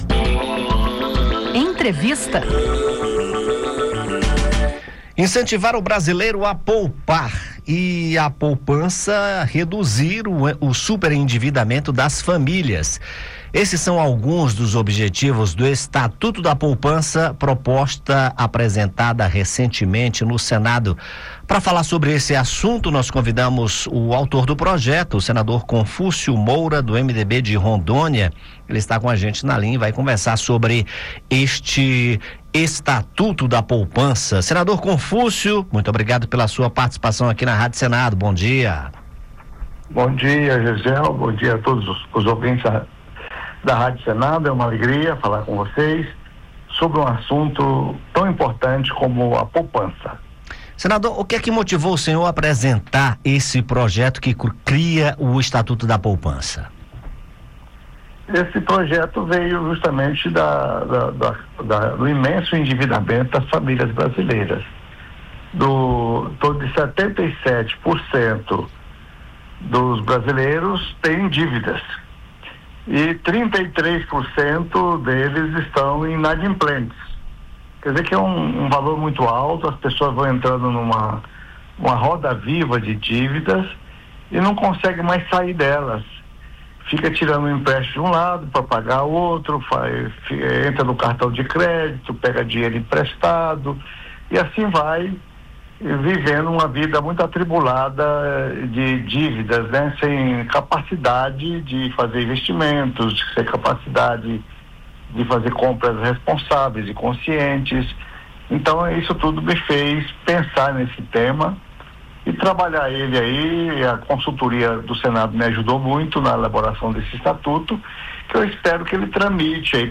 Em entrevista ao Conexão Senado, da Rádio Senado, Confúcio Moura defendeu a proposta e destacou a importância de medidas para promover a poupança e incentivar a população a poupar.